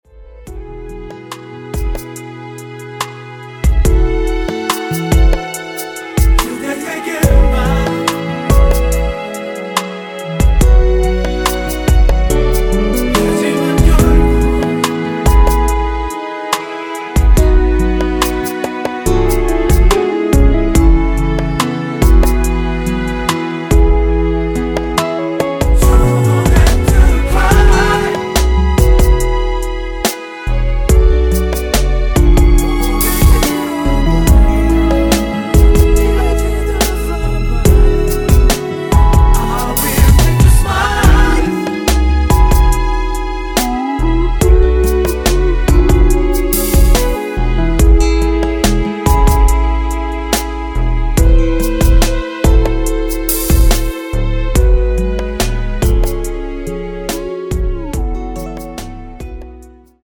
원키 코러스 포함된 MR입니다.(미리듣기 참조)
앞부분30초, 뒷부분30초씩 편집해서 올려 드리고 있습니다.